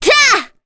Kirze-Vox_Attack6_kr.wav